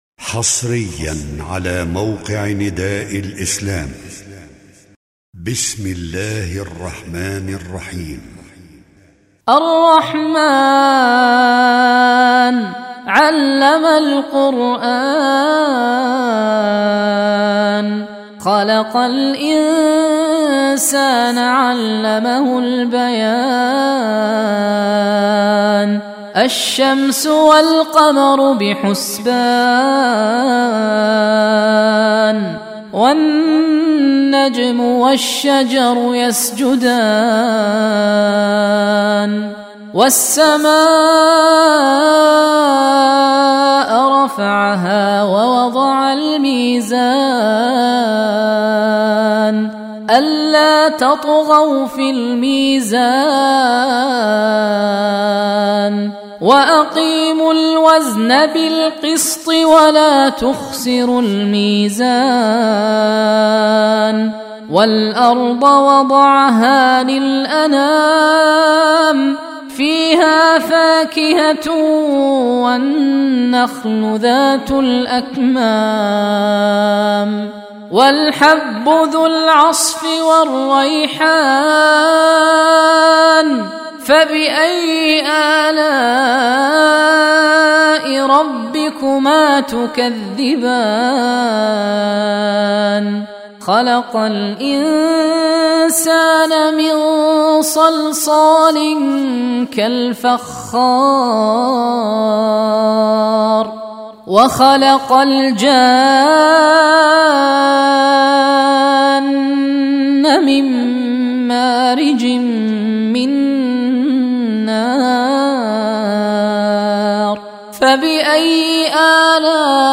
سورة الرحمن با صدای الشيخ وديع اليمني
شیخ وادی حماد الیمانی قاری برجسته ساکن پادشاهی عربستان سعودی است.